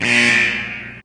klaxon.mp3